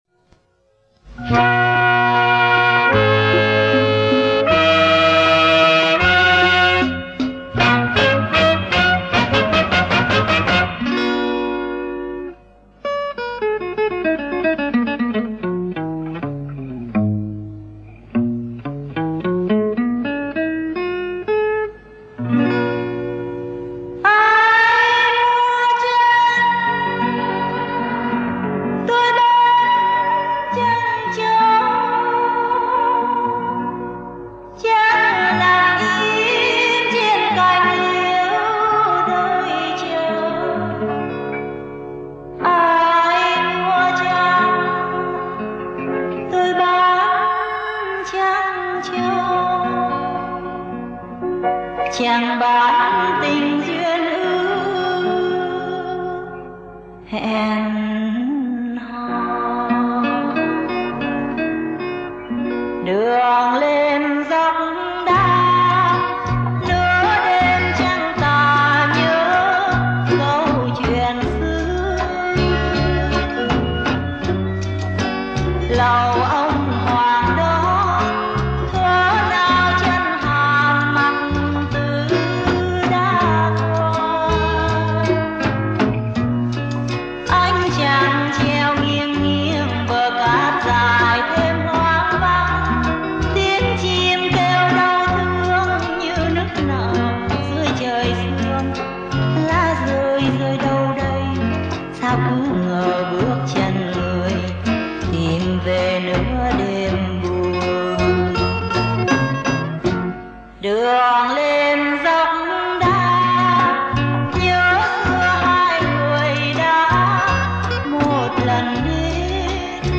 Sau đó thì tác giả chuyển qua điệu nhạc Bolero :